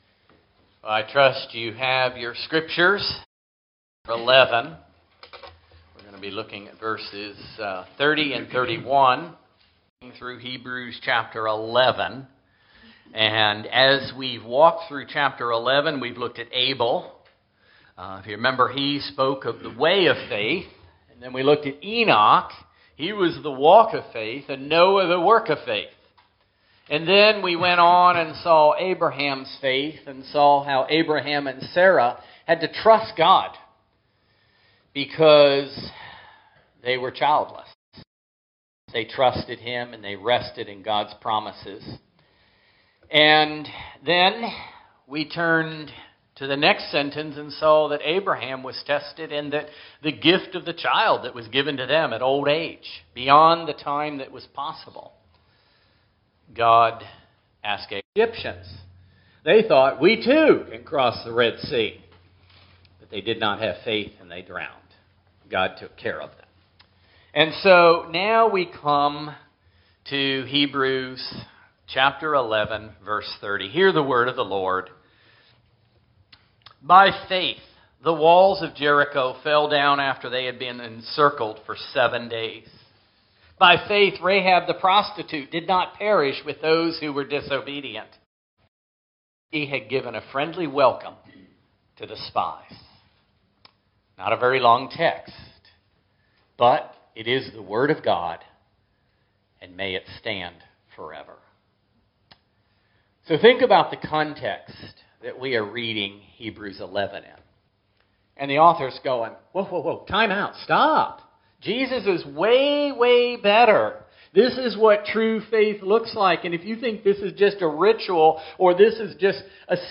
Passage: Hebrews 11:30-31 Service Type: Sunday Morning Worship